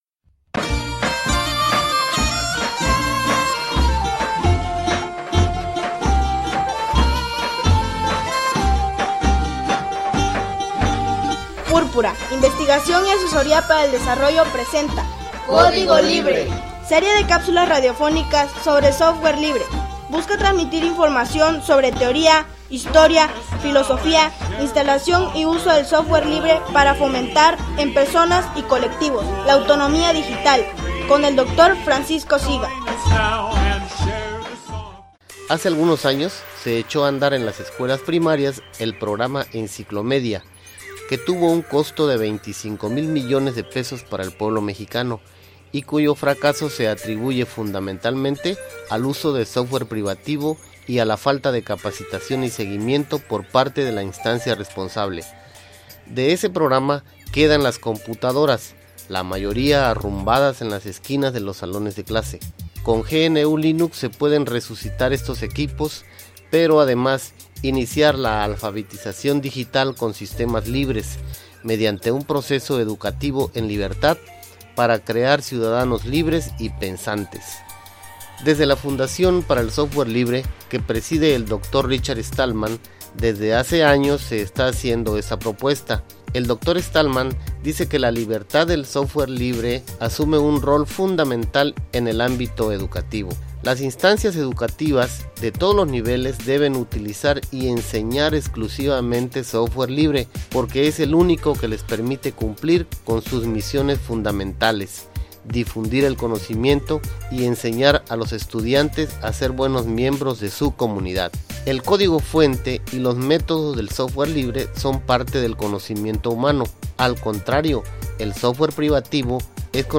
Serie de capsulas radiofónicas sobre Software Libre.